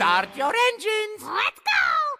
Voice clip of Mario and Toad from Mario Kart 8.